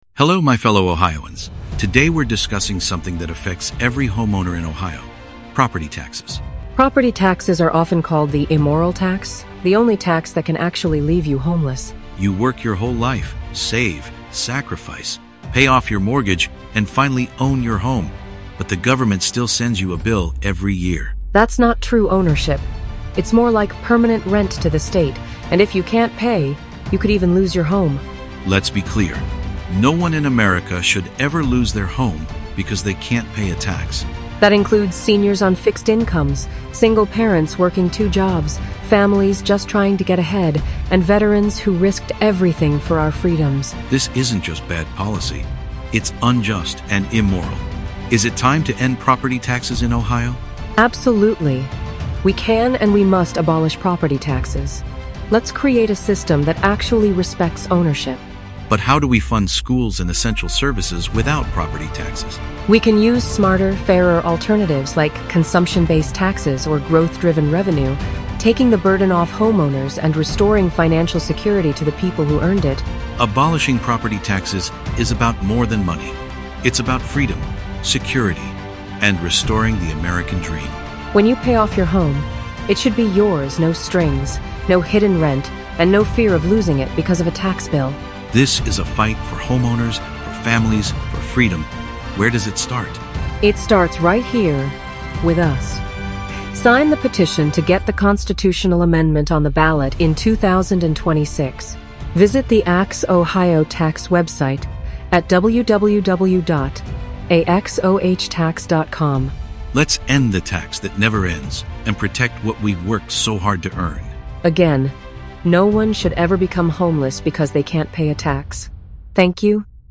Audio and Video Ads
Use these files PSA on radio or in podcasts